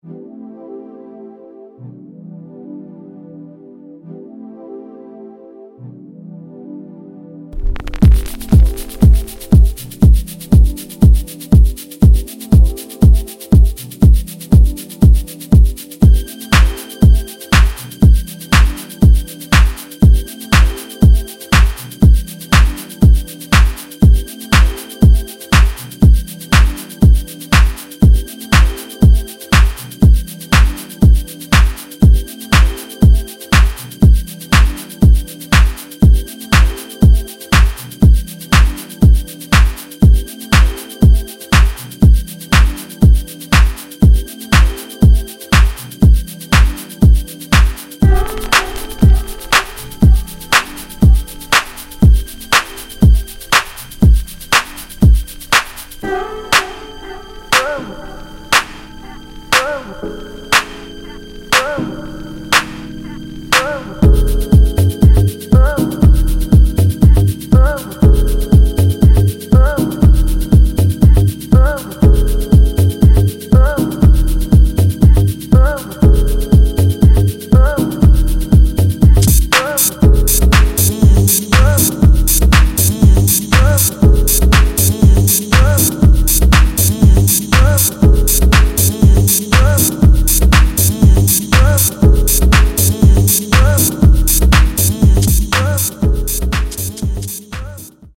Jersey house tracks for fine club abuse!
House